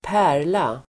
Uttal: [²p'ä:r_la]